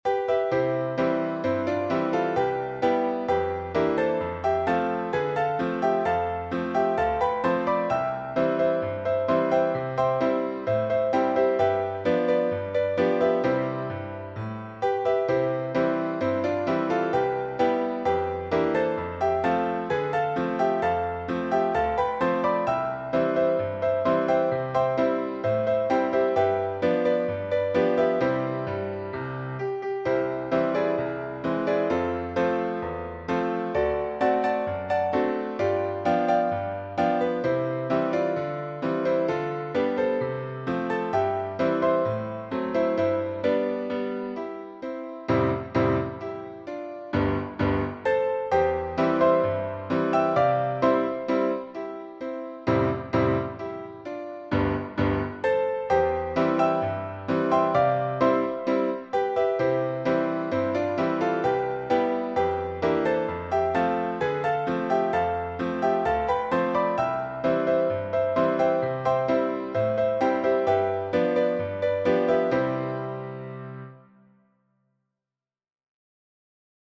old-style arrangement
Cheerful
Ragtime cover
Piano only